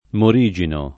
[ mor &J ino ]